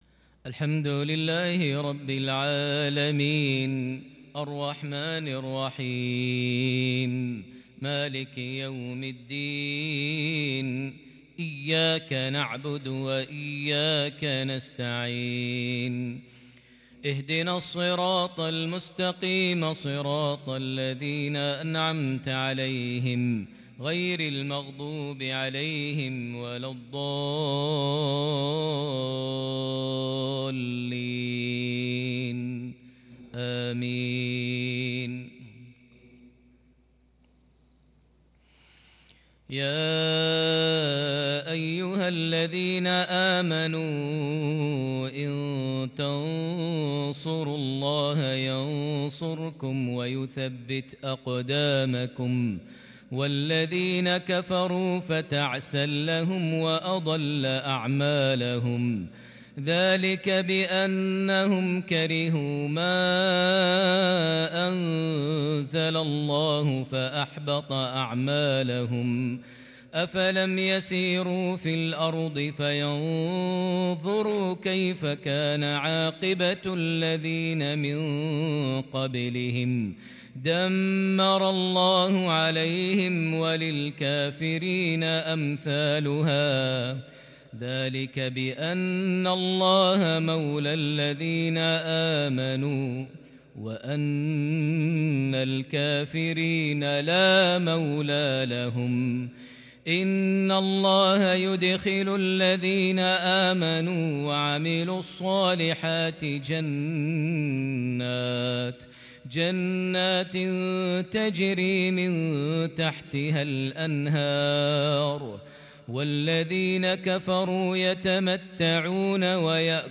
صلاة العشاء من سورة محمد 17 رجب 1442هـ | lsha 1-3-2021 prayer from Surah Muhammad 7-17 > 1442 🕋 > الفروض - تلاوات الحرمين